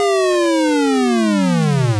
fall.ogg